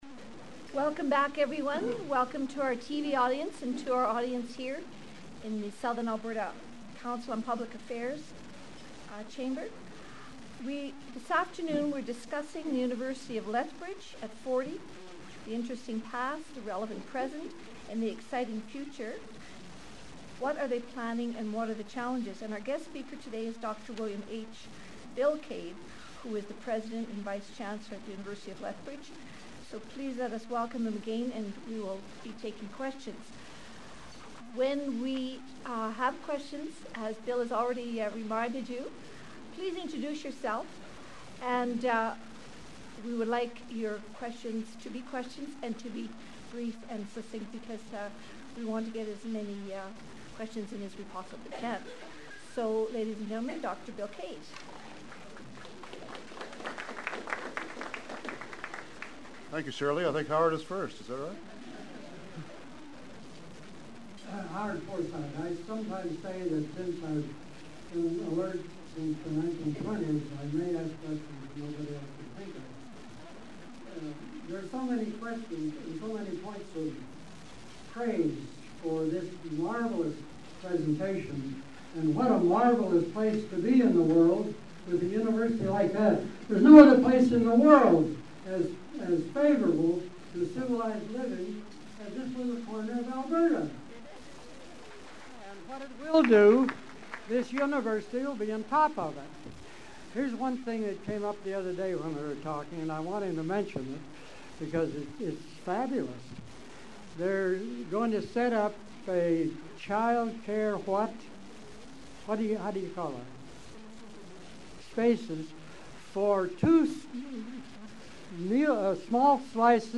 Location: Sven Ericksen¹s Family Restaurant (lower level) 1715 Mayor Magrath Drive S., Lethbridge, Alberta Time: Noon to 1:30 p.m.